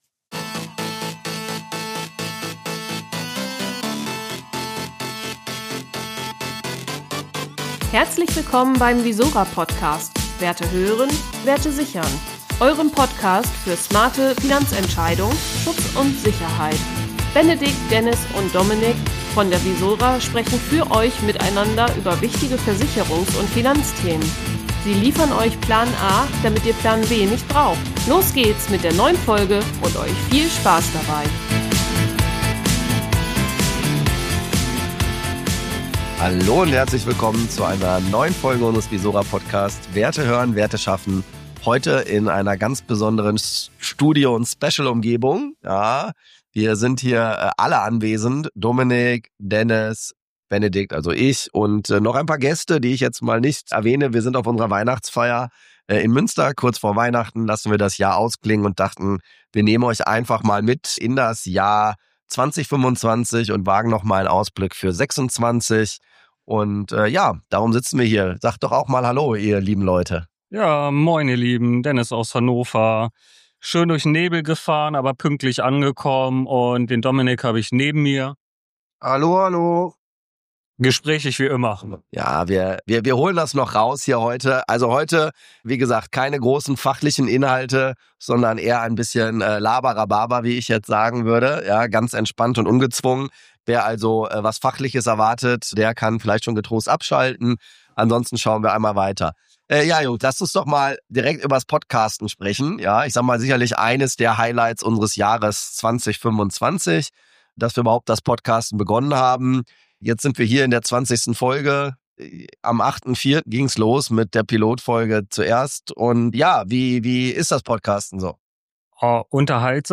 Weihnachts-Special im visora Podcast Rückblick 2025, Ausblick 2026, ehrliche Einblicke hinter die Kulissen – direkt von unserer Weihnachtsfeier  Kein Fachchinesisch, dafür Persönlichkeit, Learnings & ein Blick nach vorn.